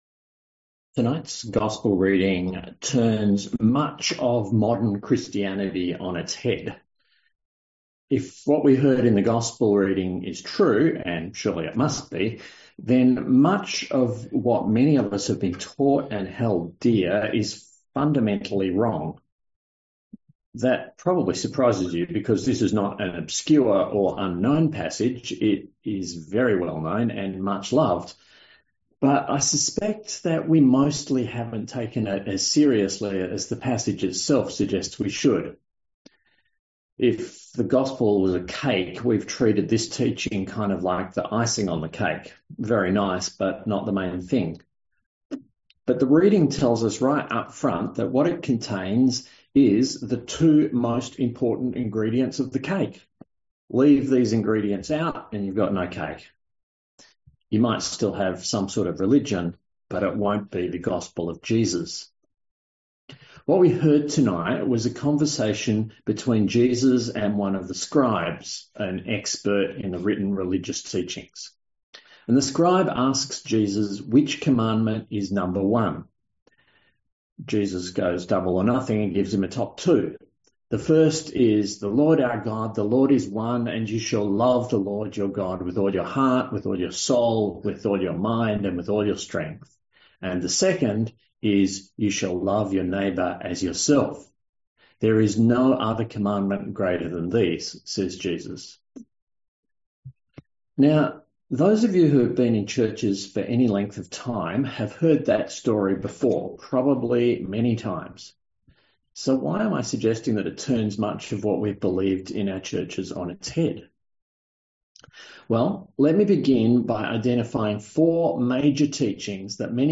A sermon on Mark 12:28-34